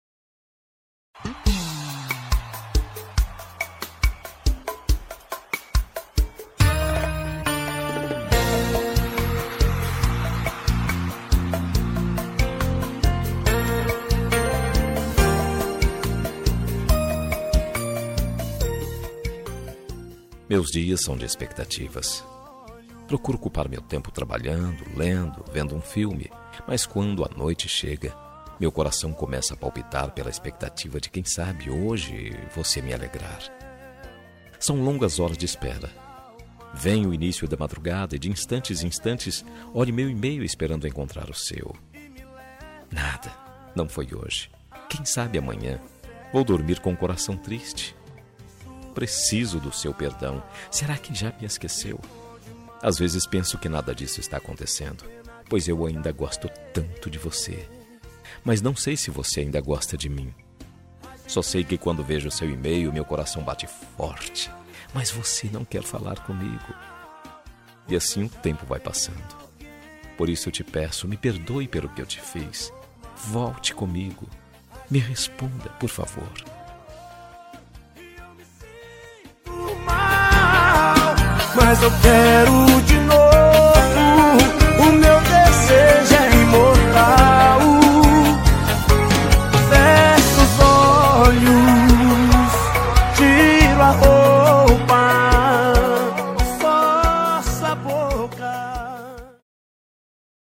Telemensagem de Reconciliação – Voz Masculina – Cód: 8003 – Linda
8003-recon-masc.m4a